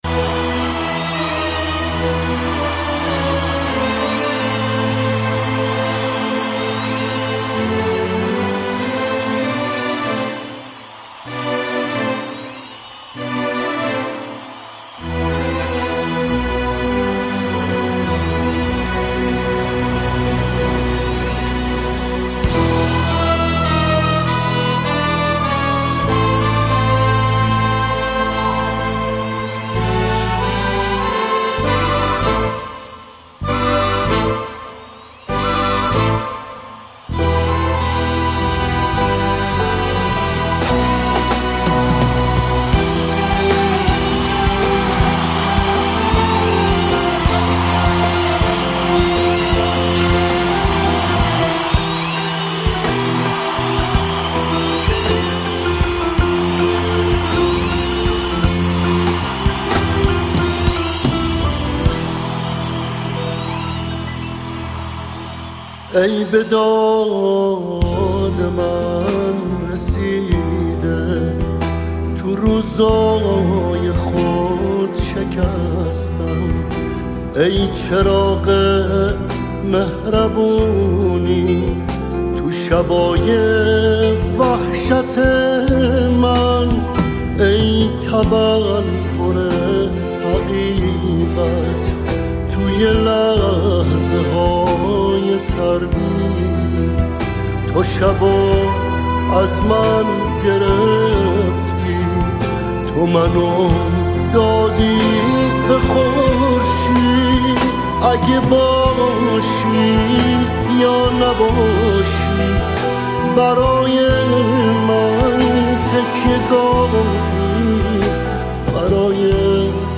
ژانر: پاپ + رپ
توضیحات: بهترین اجرای زنده در کنسرت های خوانندگان ایرانی